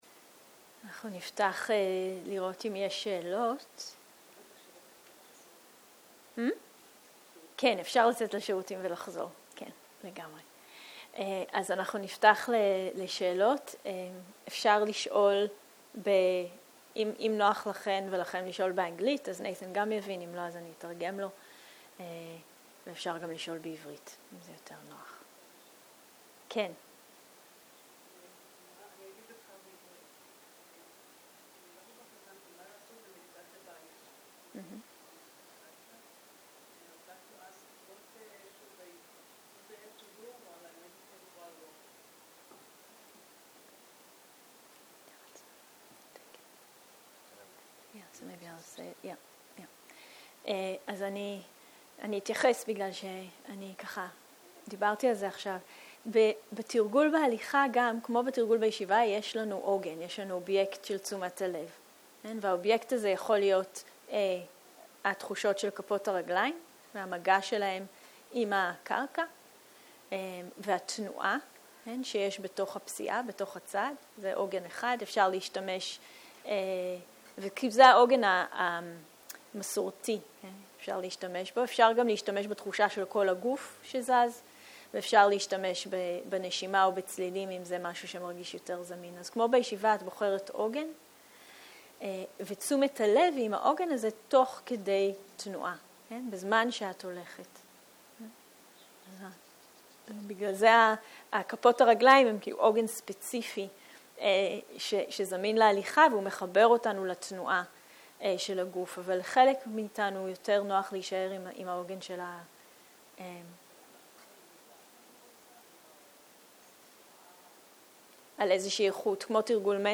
Dharma type: Questions and Answers